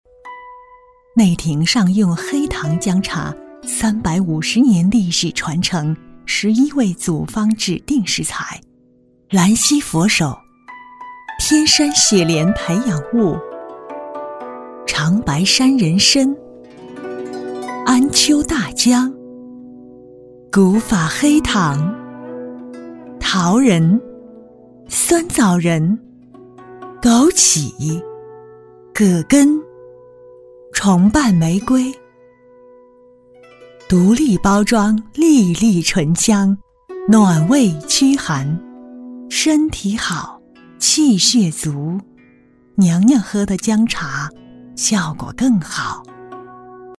女225-药材广告【北京同仁堂-品质】
女225-知性 温婉 纪实记录
女225-药材广告【北京同仁堂-品质】.mp3